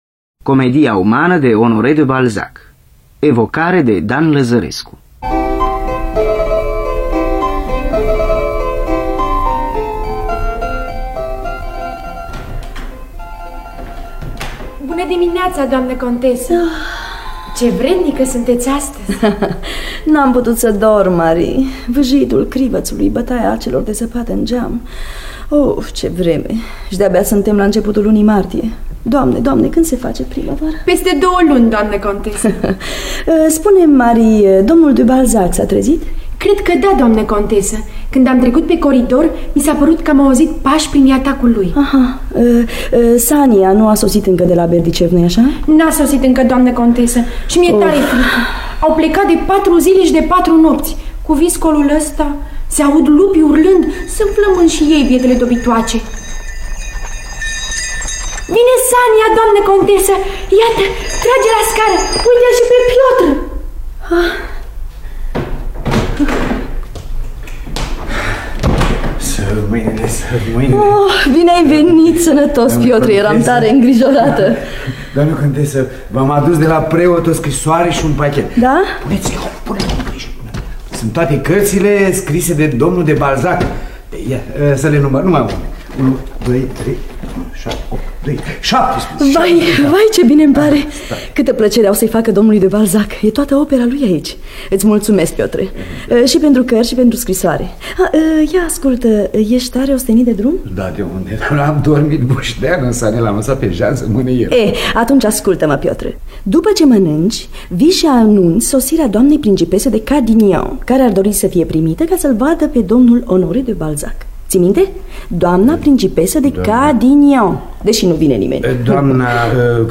Scenariu radiofonic de Dan Amedeo Lăzărescu.